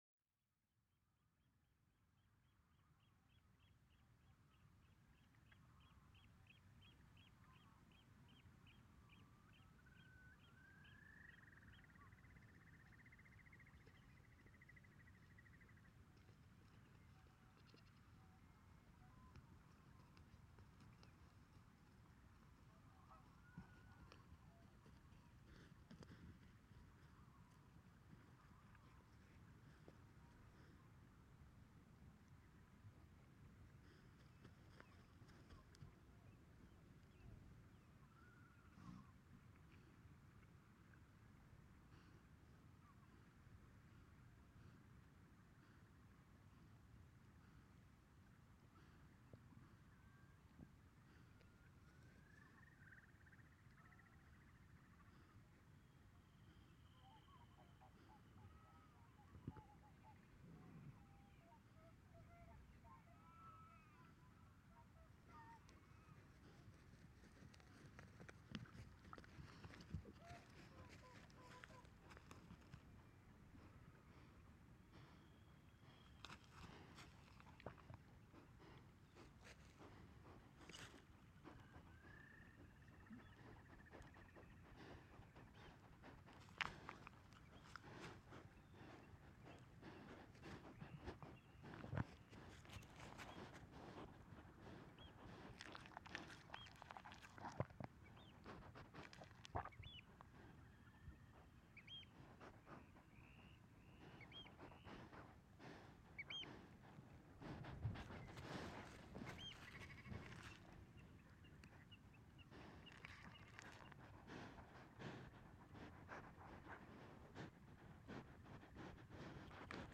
Curious sheep
Here is one of these moments when curious sheeps are sniffing, licking and biting the microphones. Not very interesting, but anyway the loudest part of seven hours long recording. It was recorded in Álftaver, in the south east south of Iceland, around midnight, 4th of June 2015.
Hér er ein slík uppákoma þegar rolla og lambrútur snuðra við hljóðnemasettið.